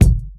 Kick31.wav